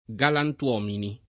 vai all'elenco alfabetico delle voci ingrandisci il carattere 100% rimpicciolisci il carattere stampa invia tramite posta elettronica codividi su Facebook galantuomo [ g alant U0 mo ] s. m.; pl. galantuomini [ g alant U0 mini ] — pop. galantomo — accr. galantomone